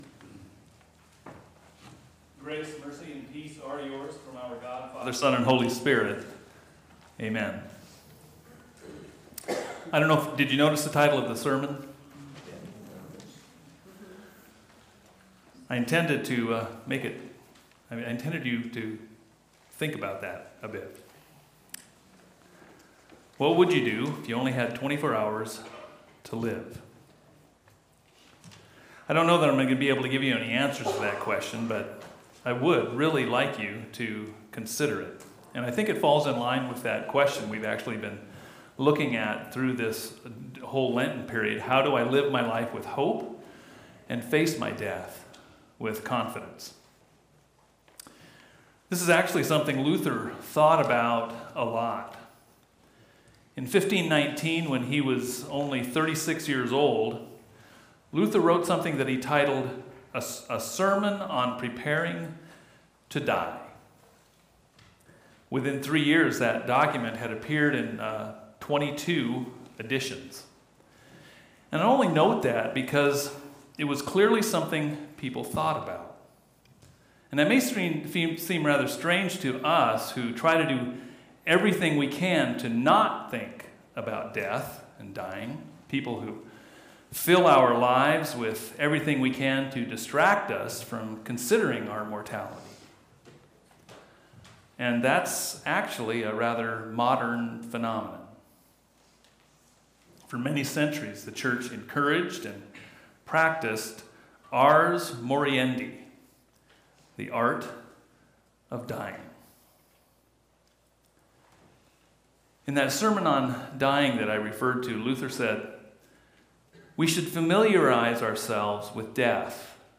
Holy Week Sermons